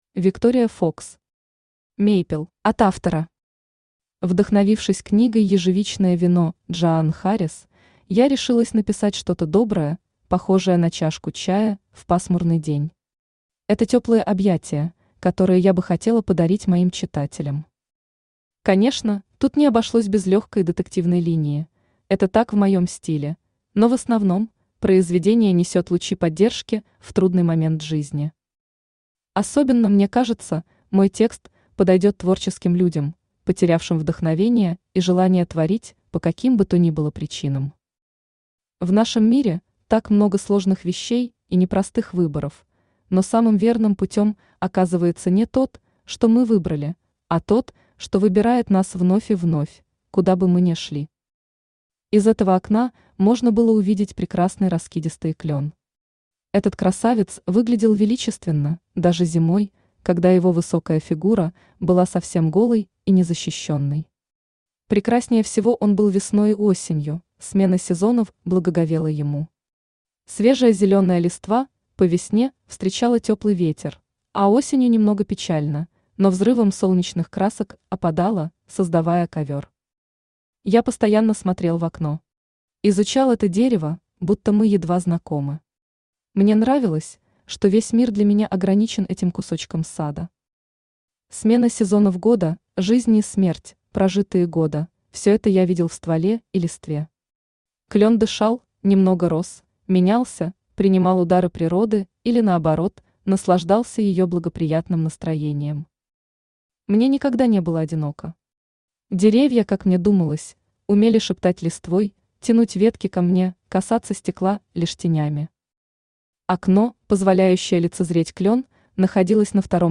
Аудиокнига Мейпел | Библиотека аудиокниг
Aудиокнига Мейпел Автор Виктория Фокс Читает аудиокнигу Авточтец ЛитРес.